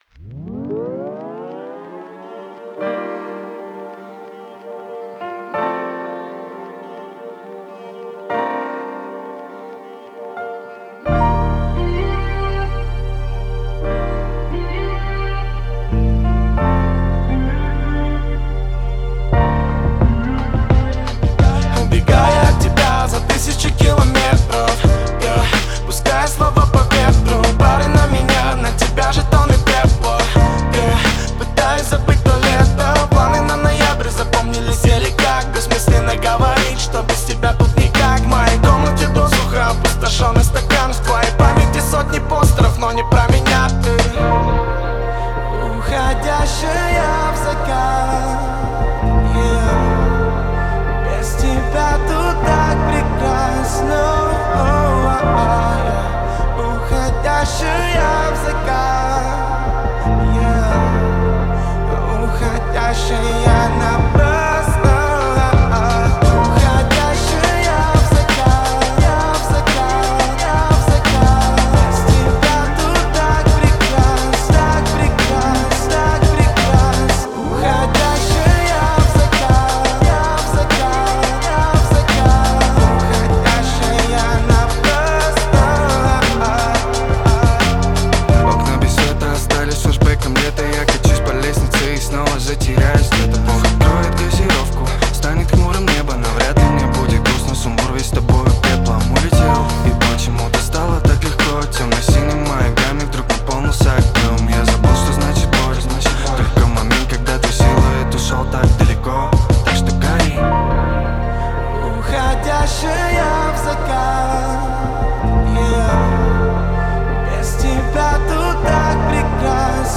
инди-поп